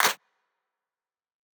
[Clp] Lofisp404.wav